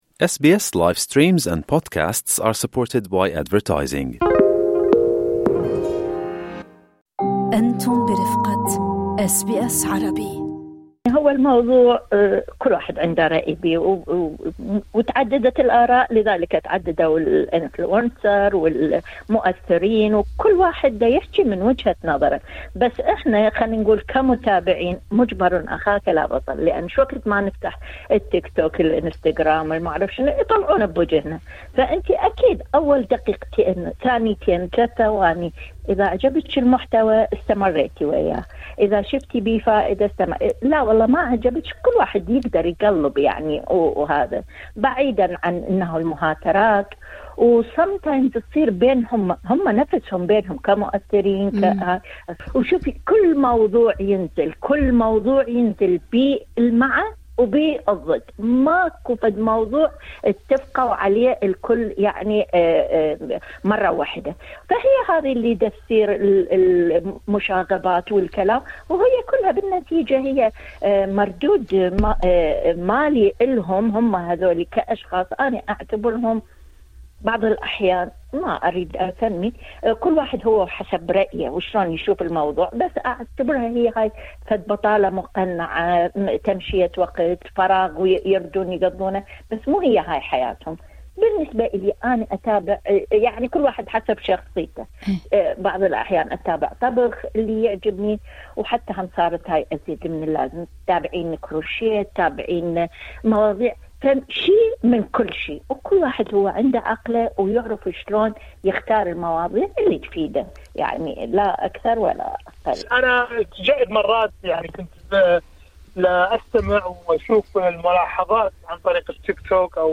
المؤثرون على وسائل التواصل الاجتماعي: ظاهرة صحية أم خطر على المجتمع؟ اليكم اراء المستمعين